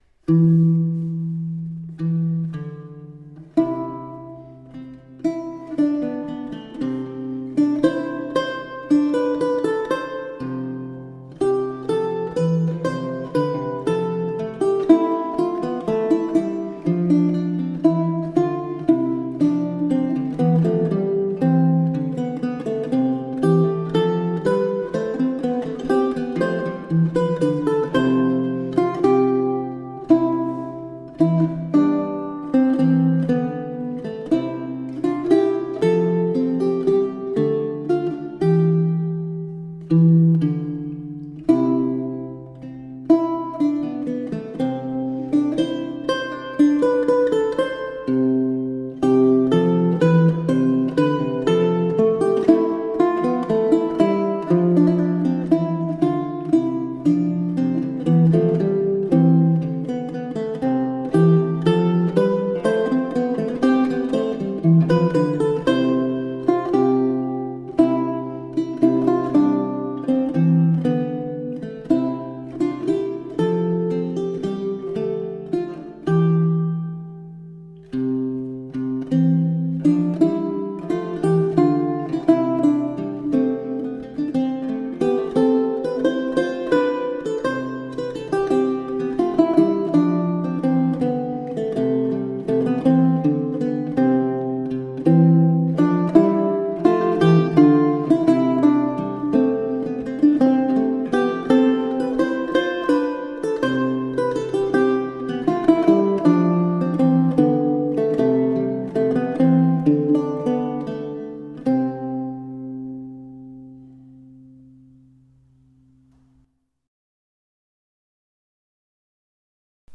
Late-medieval vocal and instrumental music.
Lute